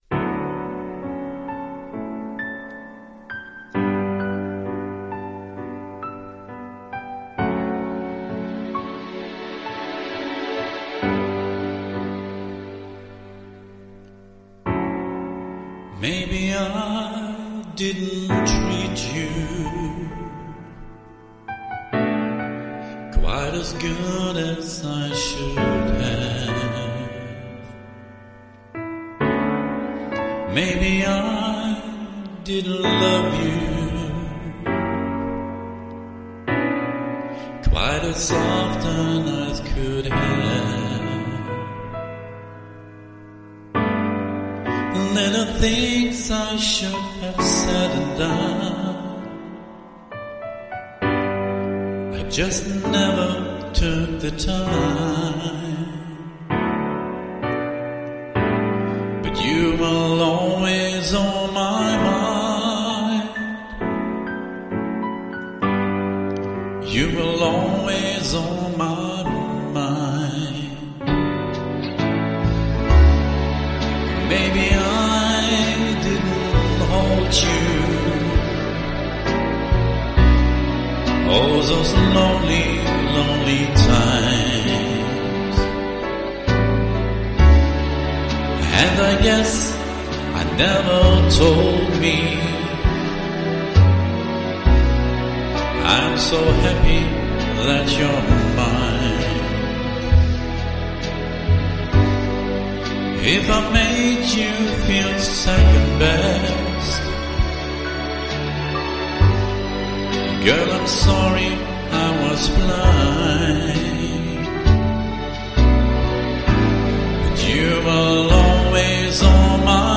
deutscher Sprecher
Sprechprobe: Sonstiges (Muttersprache):
german voice over artist